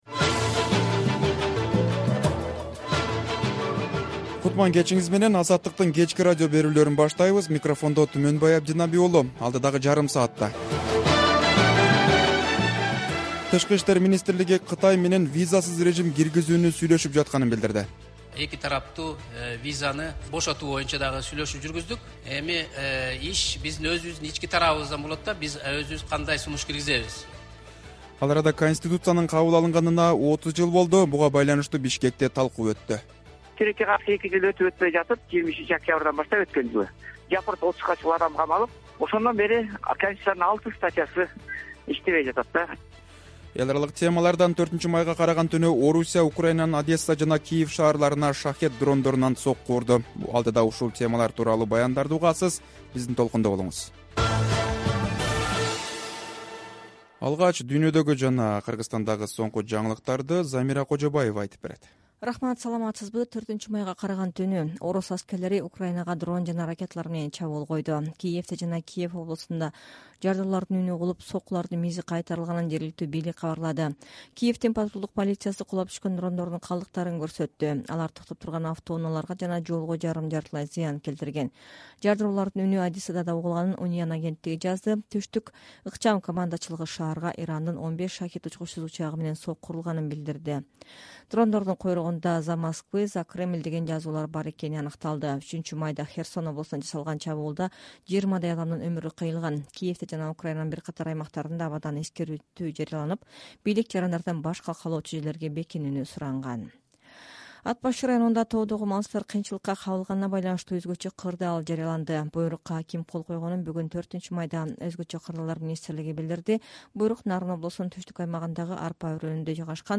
Кечки радио берүү | 4.05.2023 | ТИМ Кытай менен визасыз режимди сүйлөшүп жатканын билдирди